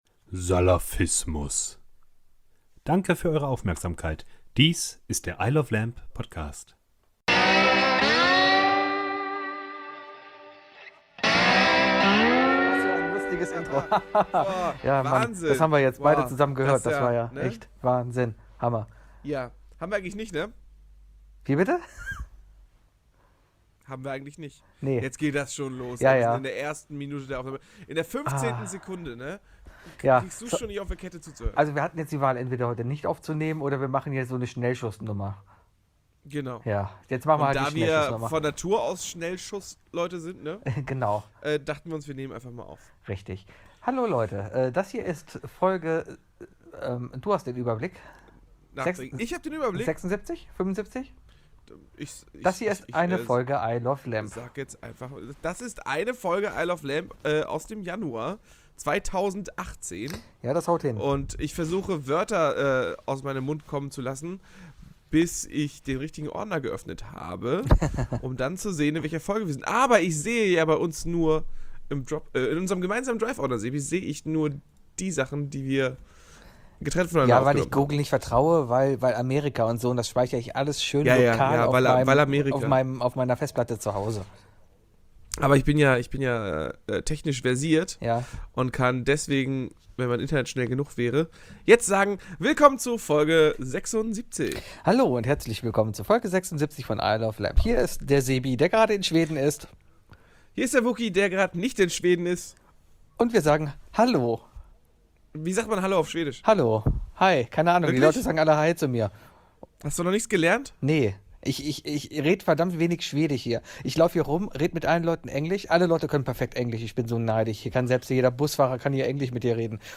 Von zwei Kölnern, die nüchtern Revue passieren lassen, was man alles im Pub sich überlegt hat. Mit Themen, über die viele reden, über die viele Meinungen existieren und wirklich von Bedeutung sind.